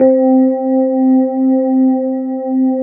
FEND1L  C3-L.wav